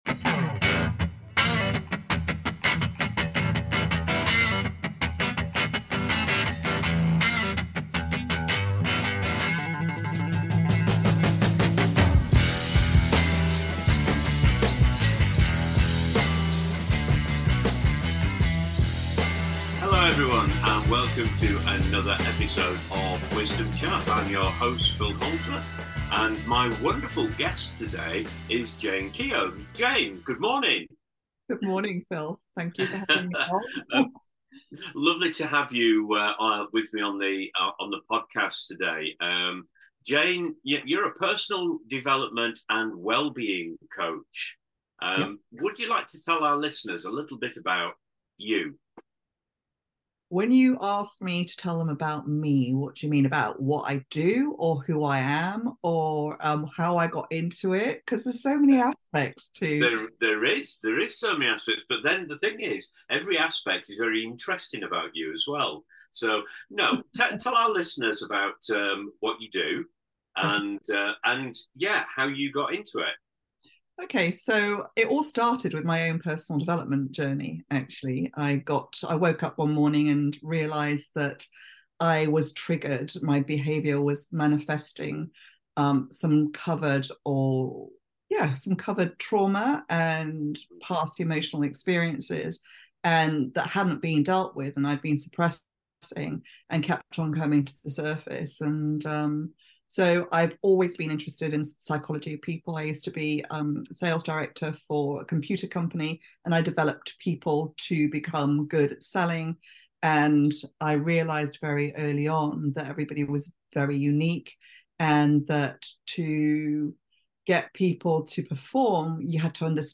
Join us for an insightful and transformative conversation that will leave you feeling inspired and motivated to take charge of your own emotional well-being.